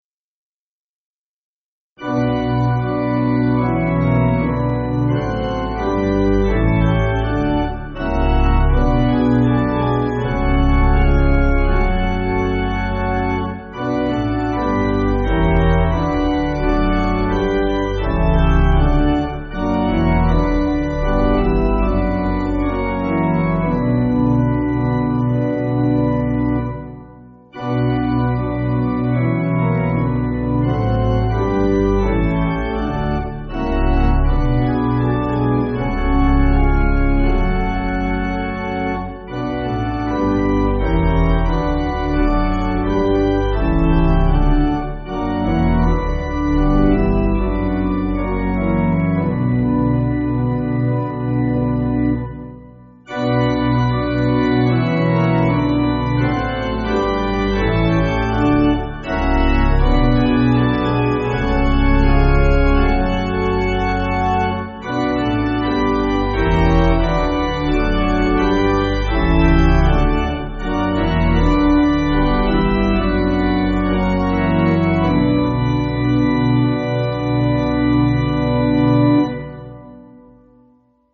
Organ
(CM)   3/Bm